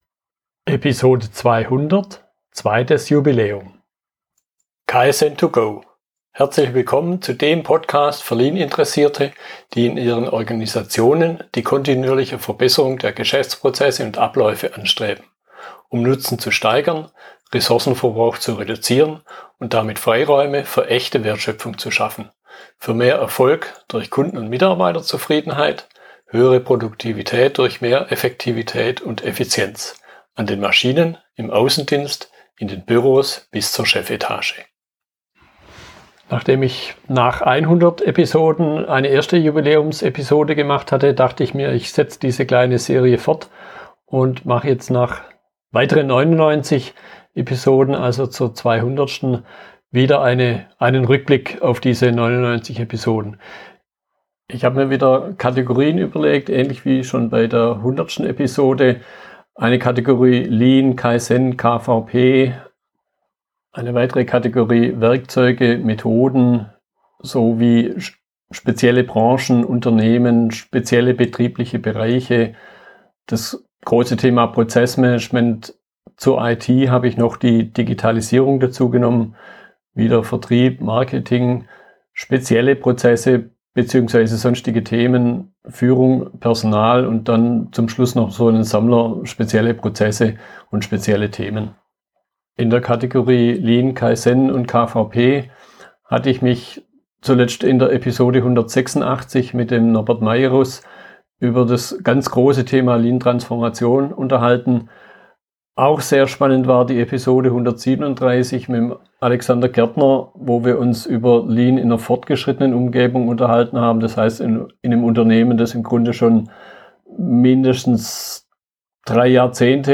Eine Solo-Episode zum 200. Jubiläum mit einem Rückblick auf die letzten 99 Episoden 101 bis 199.